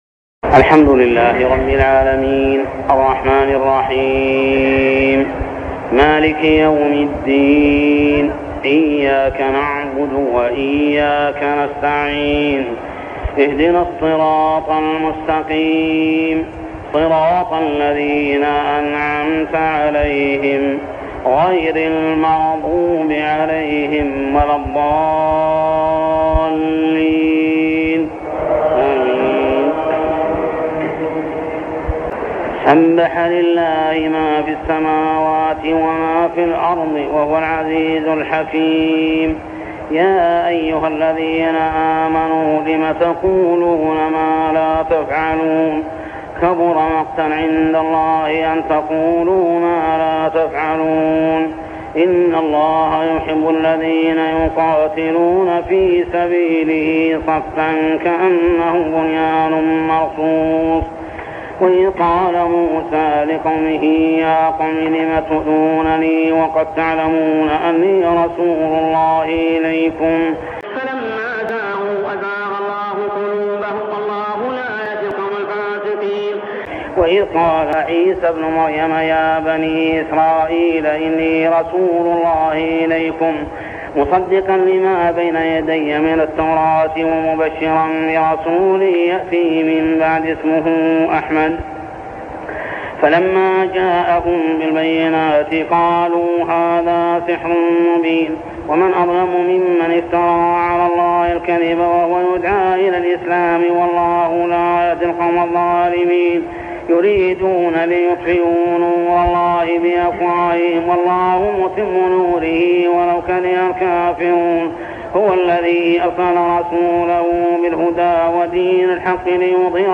من ليالي رمضان 1403هـ من سورة الصف كاملة حتى سورة الملك كاملة | Taraweeh prayer from Surah As-saff to surah Al-Mulk > تراويح الحرم المكي عام 1403 🕋 > التراويح - تلاوات الحرمين